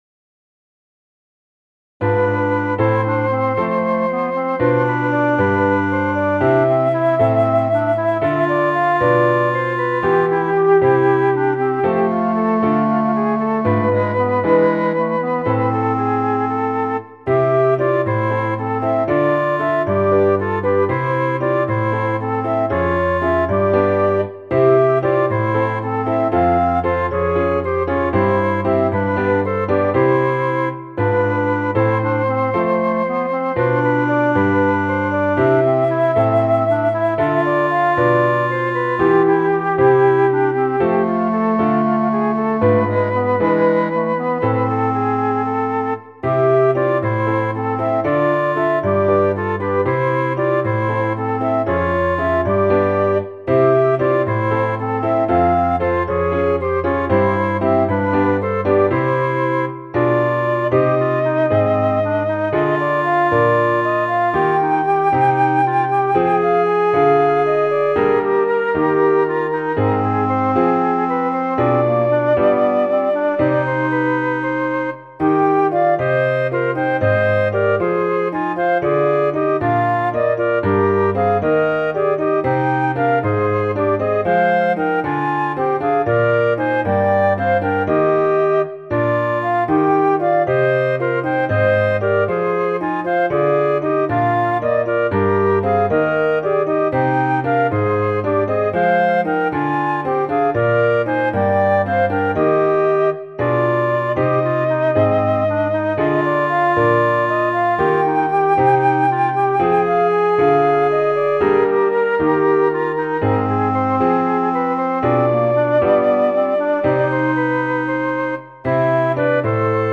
軽やかな浮遊感がある曲になりました。
• 楽器：フルート、クラリネット、ピアノ
• 主調：イ短調
• 拍子：7/8拍子
• 速度：八分音符＝232
• 楽式：ソナタ形式